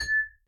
glass C
class cup ding dink effect sound tap tapping sound effect free sound royalty free Sound Effects